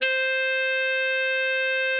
bari_sax_072.wav